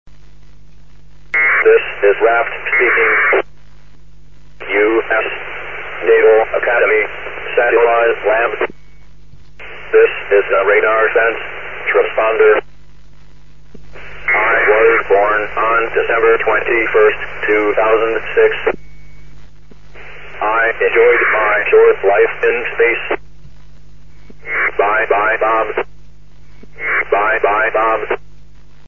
raft_voice_goodbye.mp3